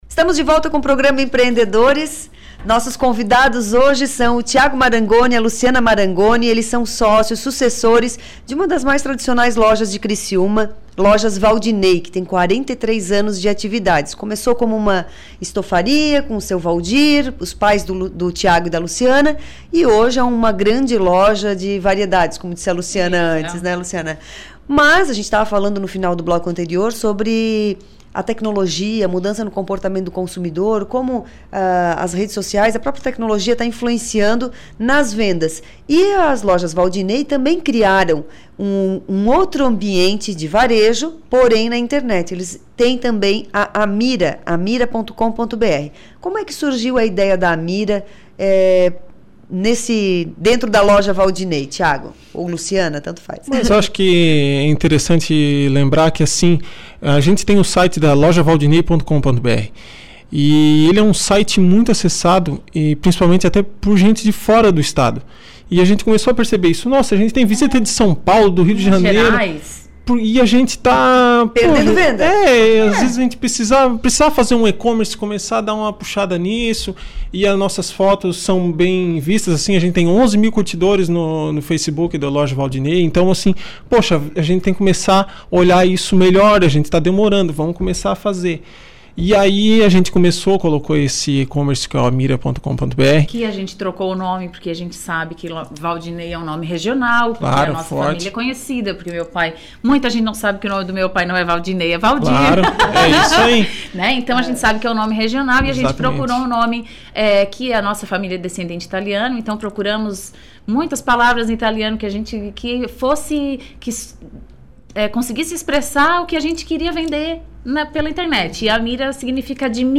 Entrevista
O Programa Empreendedores é veiculado originalmente na Rádio Som Maior.